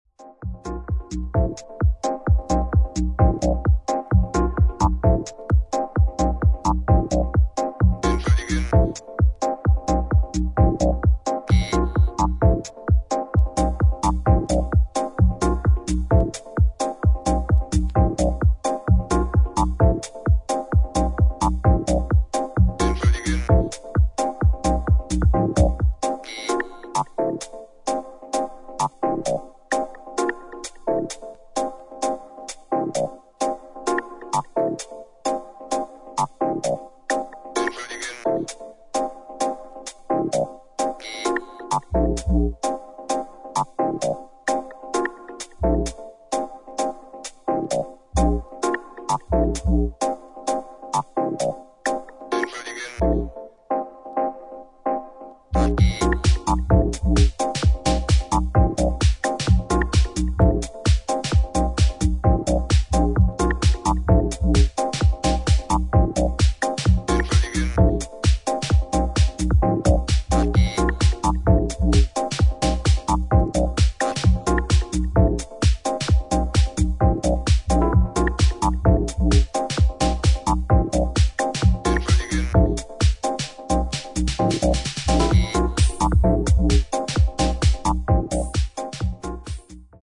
新旧どのテクノ・ハウスサウンドにも対応するであろう、円熟味を帯びた一枚です。